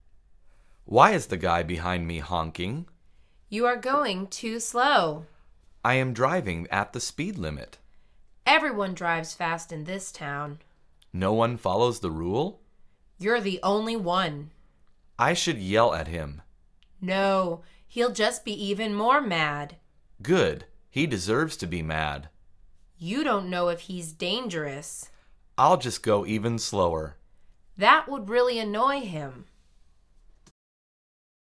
مجموعه مکالمات ساده و آسان انگلیسی: آروم رفتن